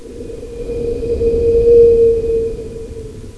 1 channel
wind0.wav